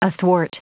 Prononciation du mot athwart en anglais (fichier audio)
Prononciation du mot : athwart